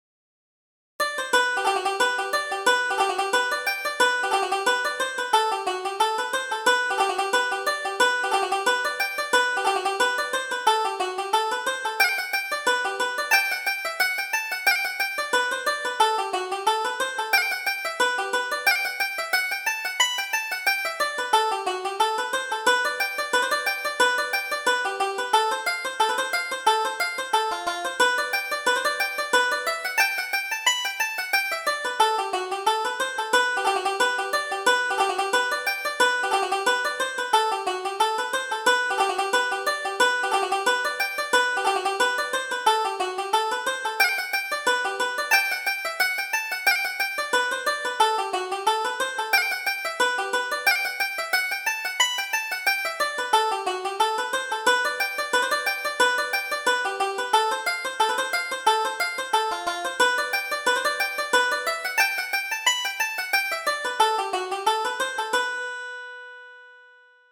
Reel: The Flogging Reel